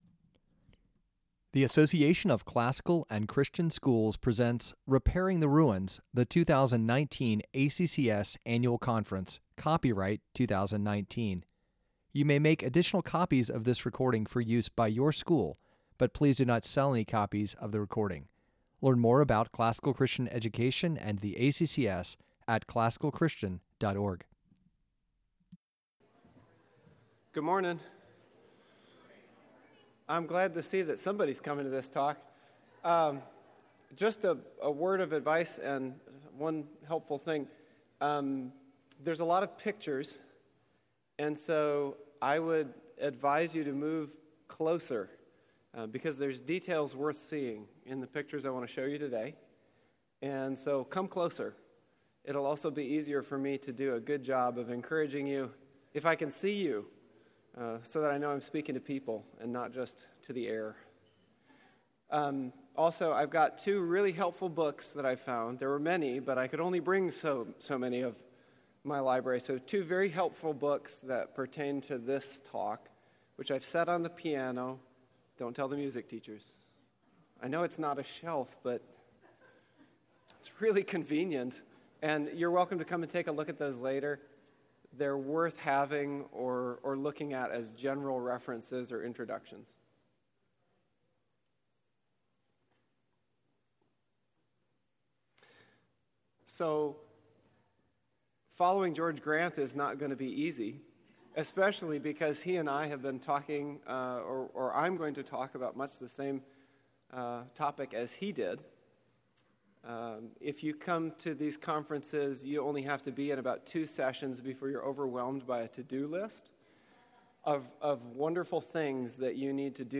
2019 Workshop Talk | 01:04:59 | All Grade Levels, Philosophy